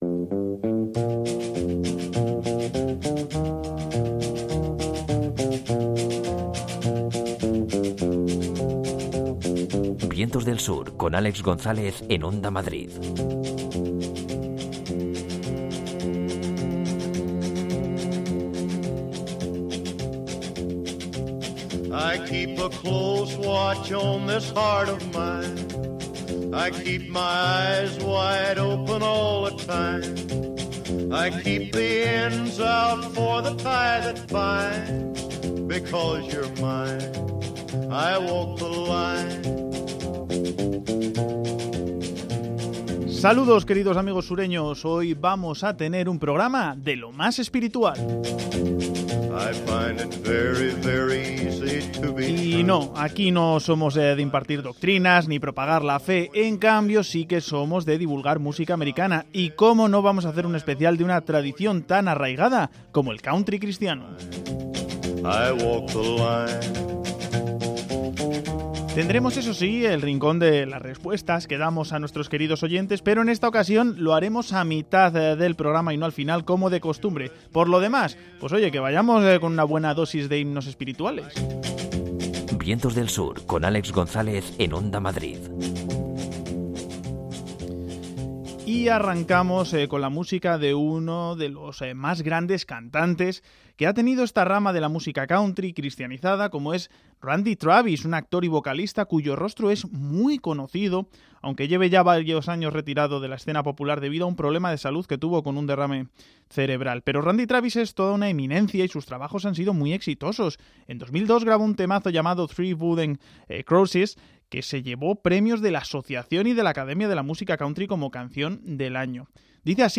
música sureña más espiritual